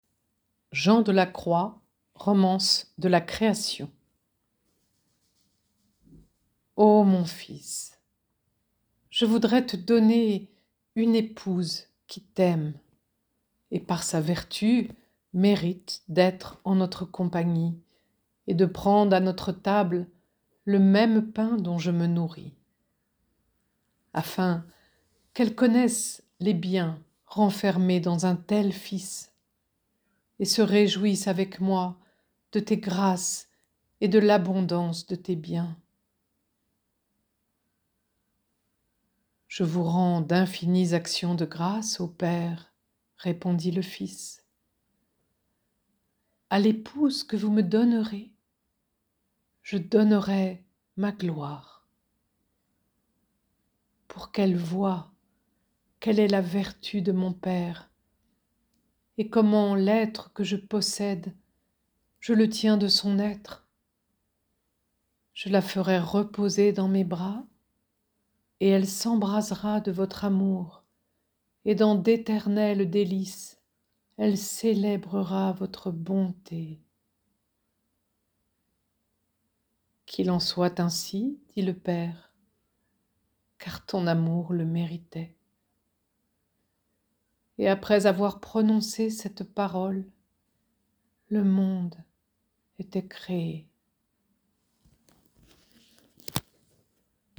Narratrice
50 - 70 ans - Contralto